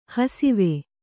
♪ hasive